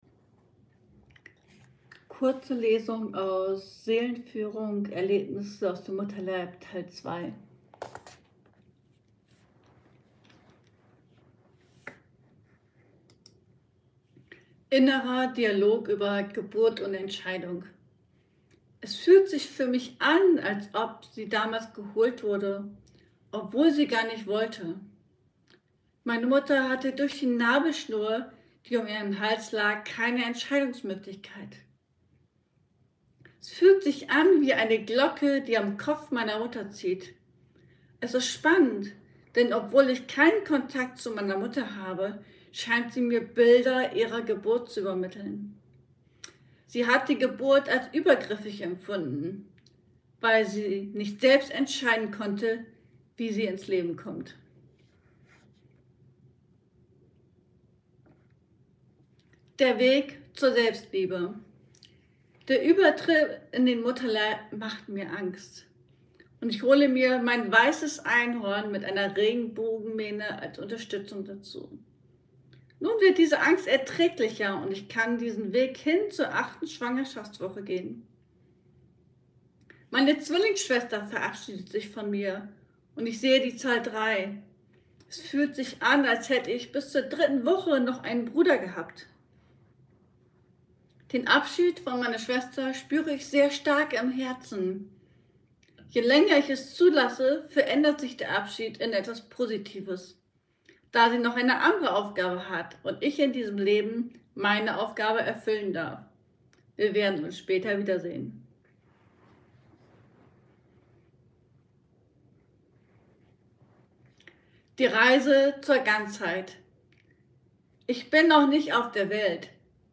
Lesung